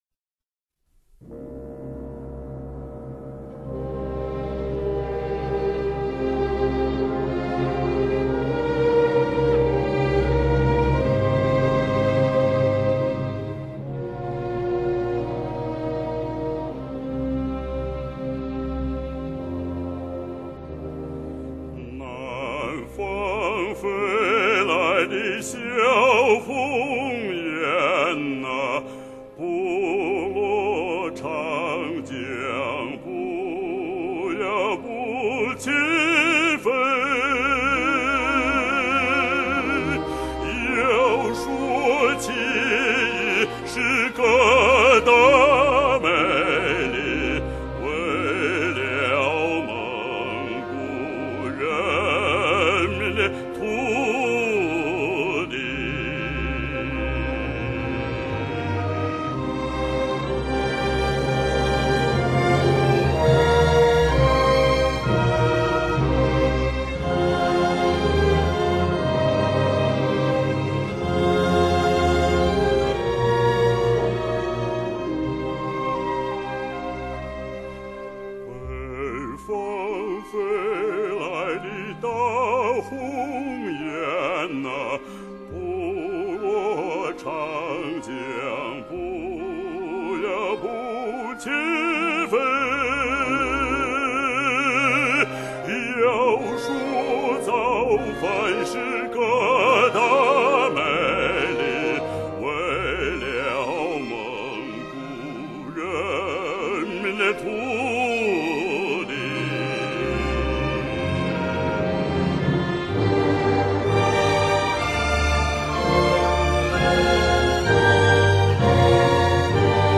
蒙古民歌